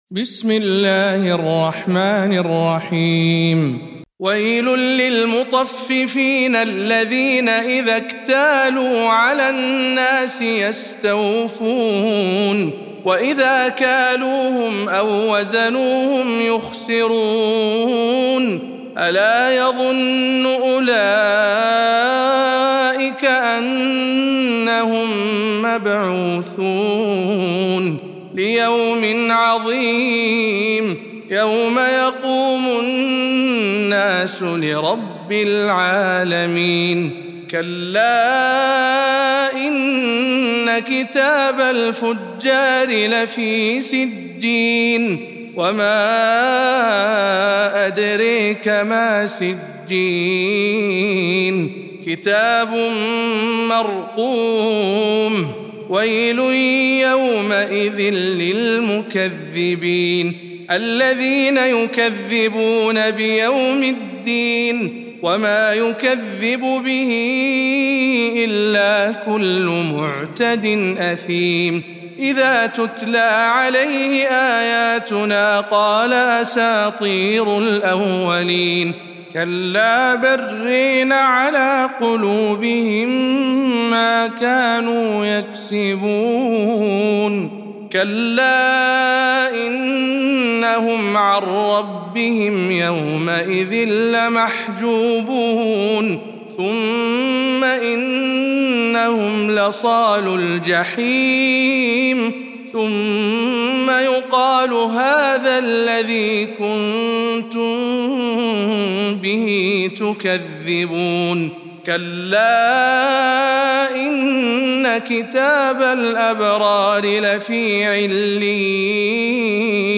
سورة المطففين - رواية ابن ذكوان عن ابن عامر